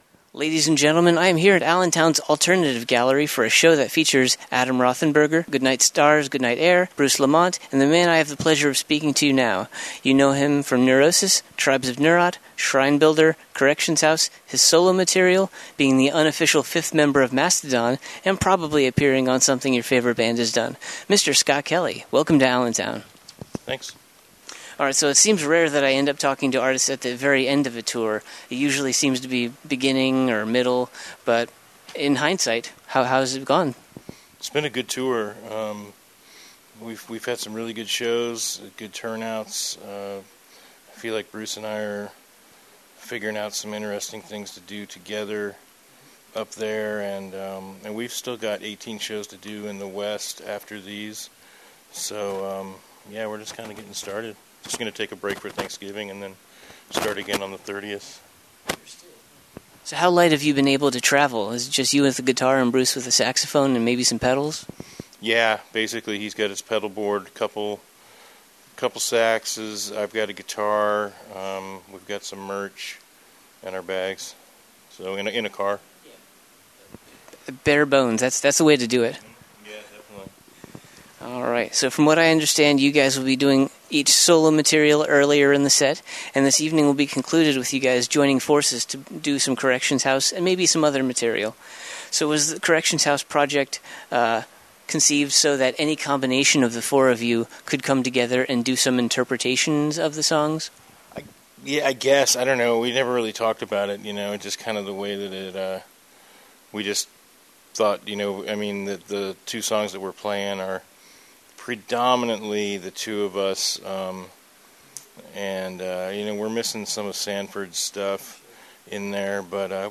Before playing his set, I had the pleasure of being able to speak to Scott. We covered a number of his musical projects, his many collaborations, Neurosis, his now-defunct radio show, Corrections House, Neurot Records, MMA, and more.
66-interview-neurosis.mp3